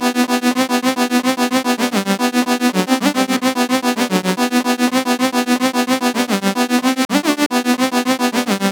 From soulful vocal chops and hypnotic melodies to groovy basslines and punchy drum loops, each sample captures the essence of Ritviz’s unique style.
Gully-Loops-Dhoop-Drop-Loop-BPM-110-E-Min.wav